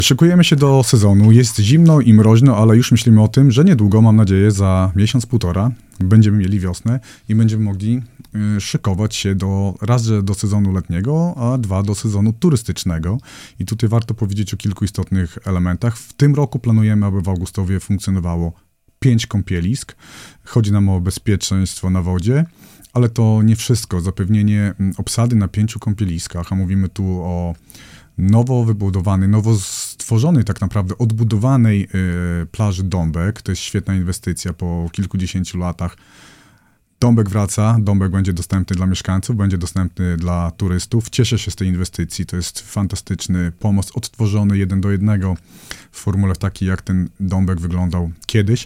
– W tym roku do listy dopisujemy plażę Dąbek z nowym pomostem – mówi Sławomir Sieczkowski, zastępca burmistrza Augustowa.